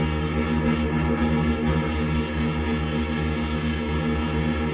sci-fi_forcefield_hum_loop_06.wav